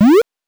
8 bits Elements
powerup_32.wav